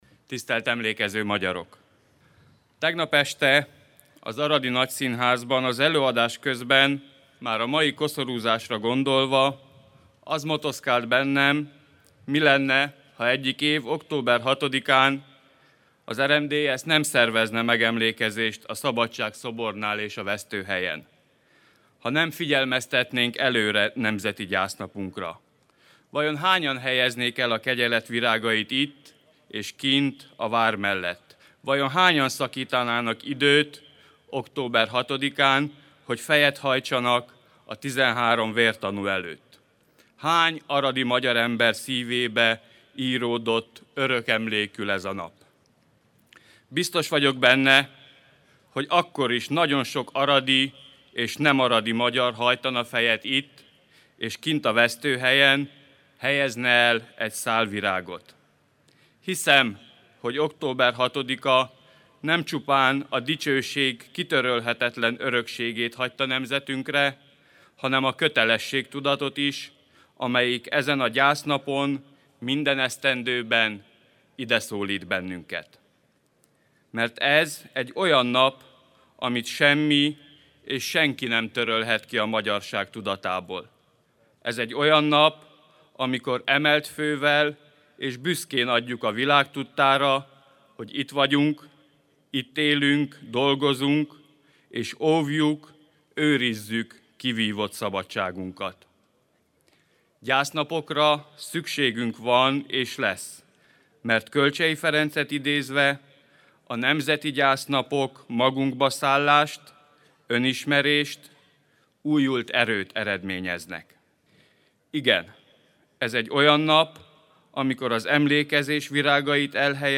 „Hiszem, hogy október 6. nem csupán a dicsőség kitörölhetetlen örökségét hagyta nemzetünkre, hanem a kötelességtudatot is, amelyik ezen a gyásznapon minden esztendőben ideszólít bennünket. Mert ez egy olyan nap, amit semmi és senki nem törölhet ki a magyarság tudatából. Ez egy olyan nap, amikor emelt fővel és büszkén adjuk a világ tudtára, hogy itt vagyunk, itt élünk, dolgozunk, és óvjuk, őrizzük kivívott szabadságunkat" – mondta a honatya a Szabadság-szobornál tartott beszédében.
farago_peter_beszede_2018_oktober_6-an.mp3